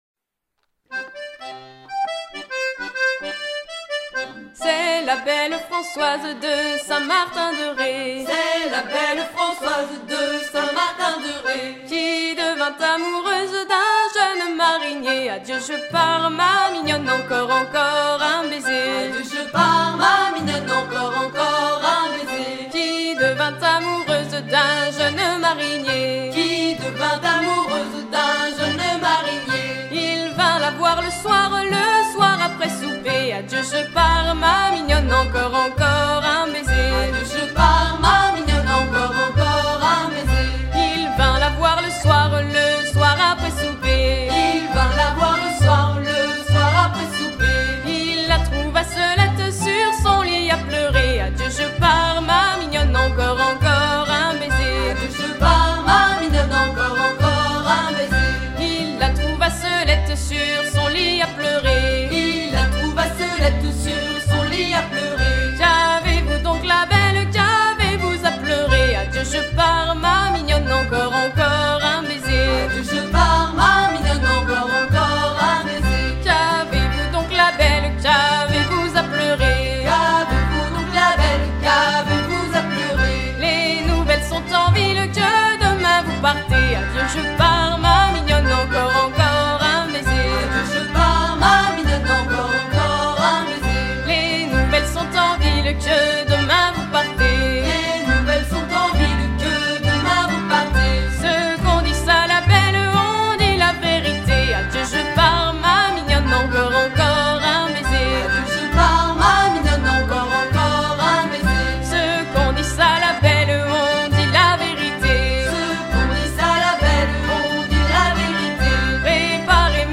danse : laridé, ridée
Genre laisse
Pièce musicale éditée